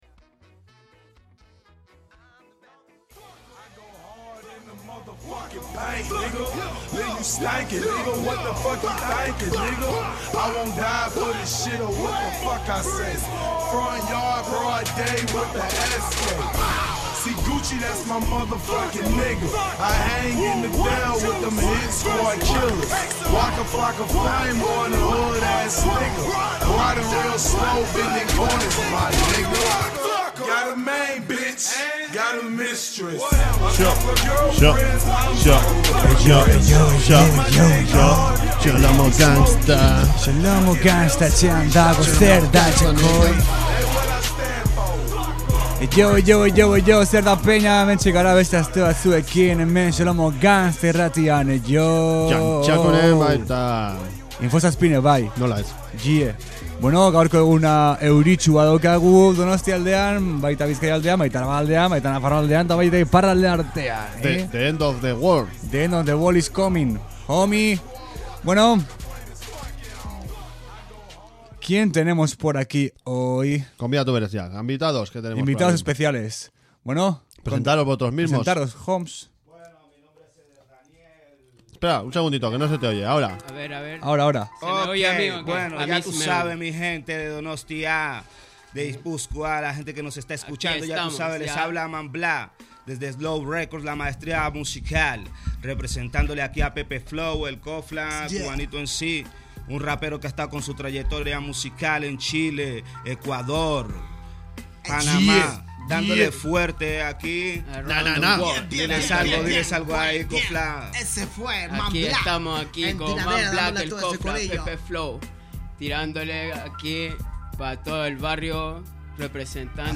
Euskal Herriko eta nazioarteko rap musika entzugai Xolomo Gangsta saioan.